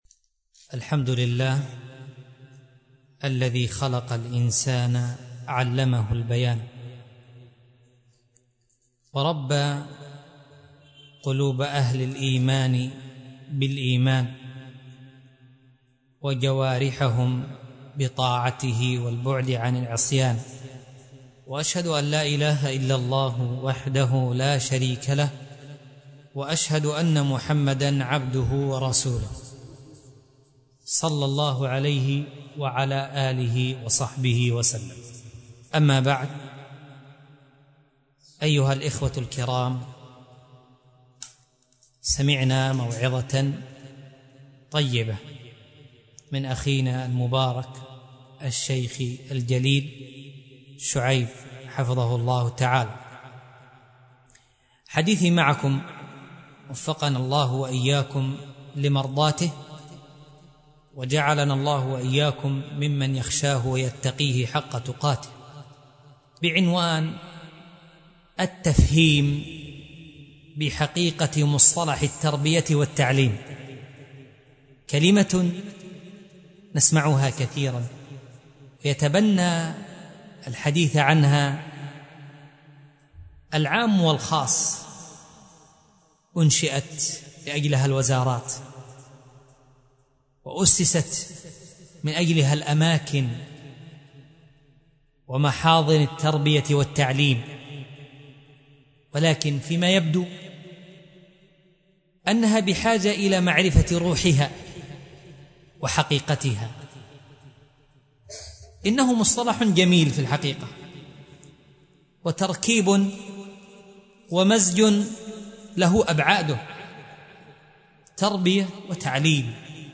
مسجد السلام / مصر القاهرة